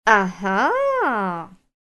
Звуки подозрительные, музыка
Погрузитесь в атмосферу тайны и саспенса с нашей коллекцией подозрительной музыки и звуков.